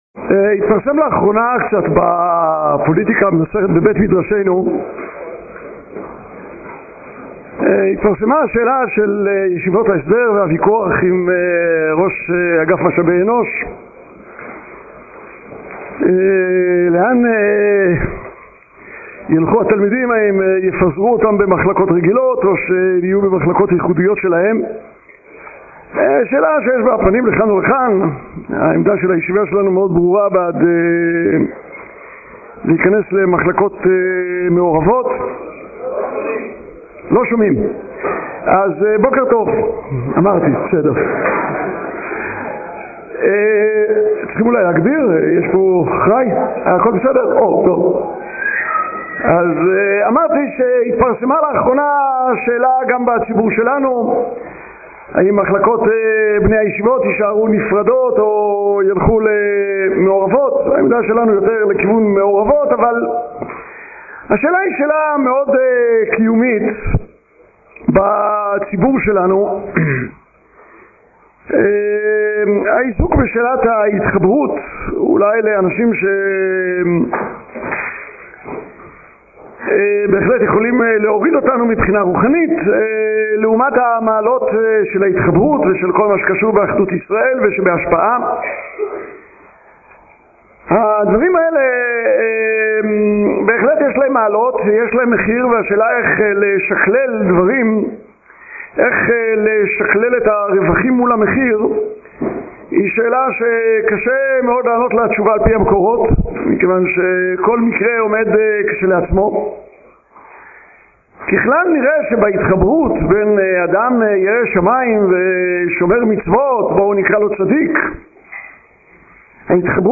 השיעור באדיבות אתר התנ"ך וניתן במסגרת ימי העיון בתנ"ך של המכללה האקדמית הרצוג תשס"ז